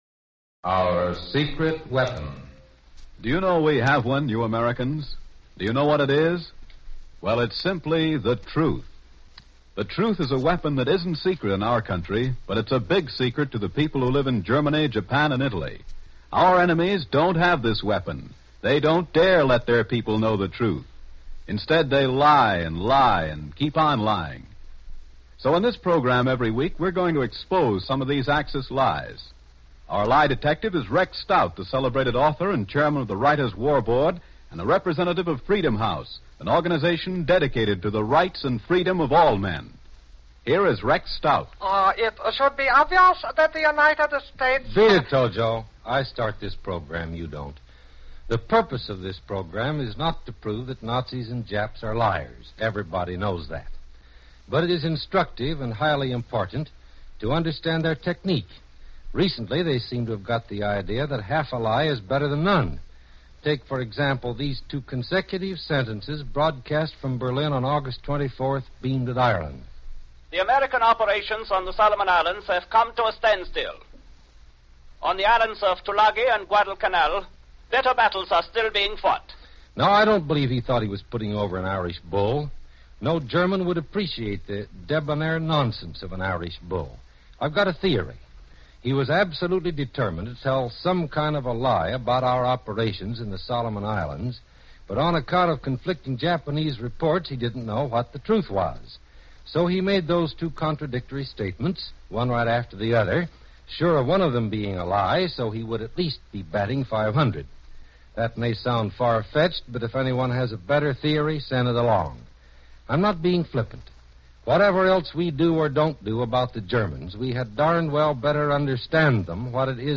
"Our Secrect Weapon" radio broadcast